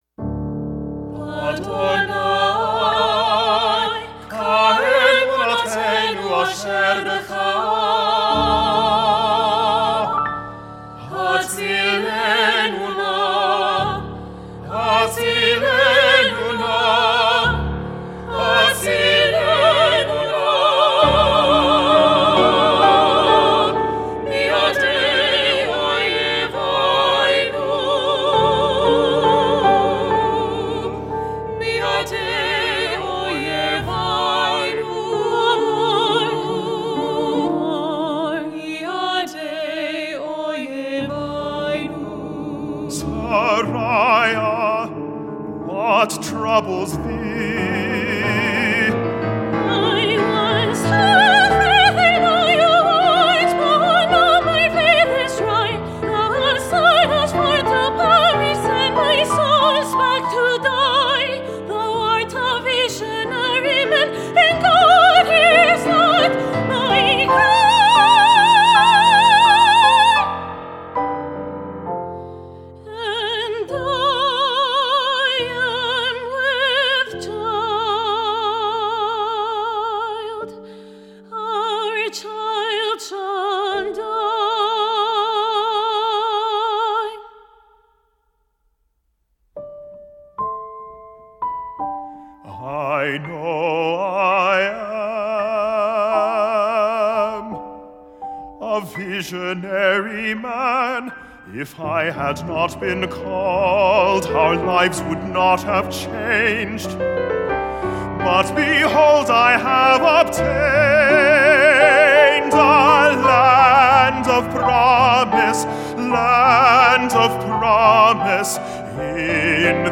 Opera Chorus
Piano OR Fl, Ob, Cl, Bn, Hn, Tr, Perc, Hp, Pno, and Str
Quartet (Lehi, Sariah, Abigail, Rebekah)